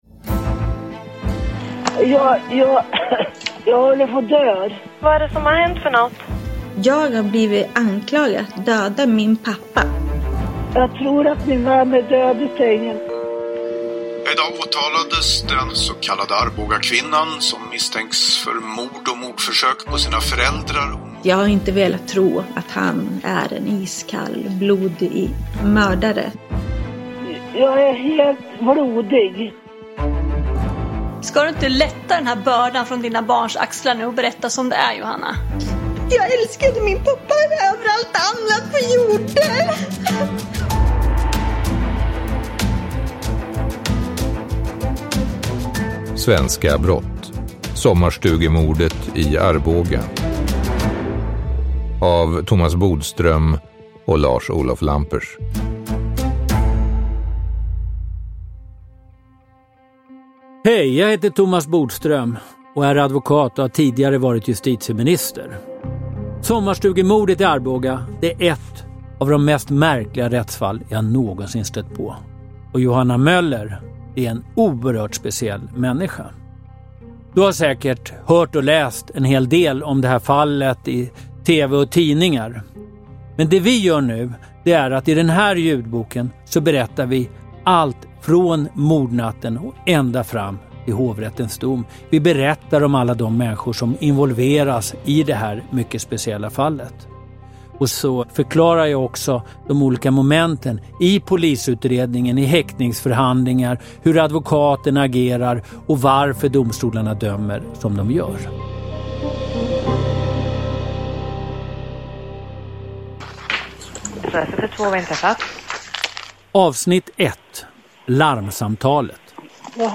Sommarstugemordet i Arboga – Ljudbok – Laddas ner